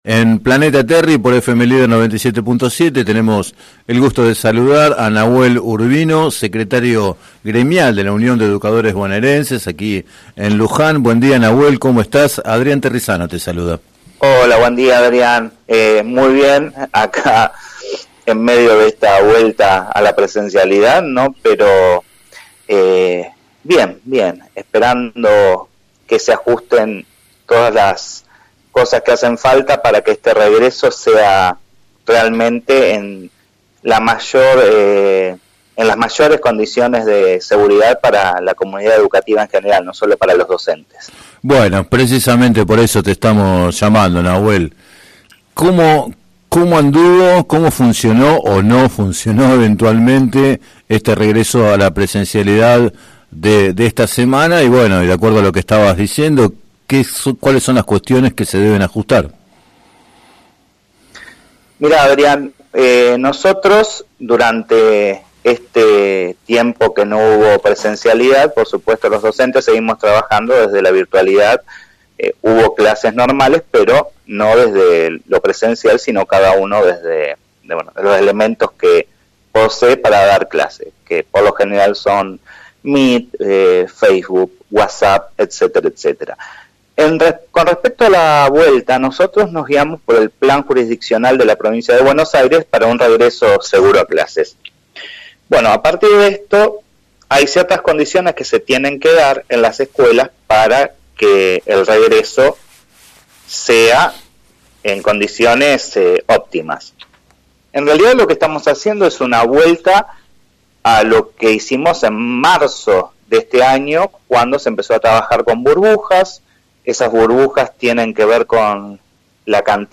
en declaraciones al programa “Planeta Terri” de FM Líder 97.7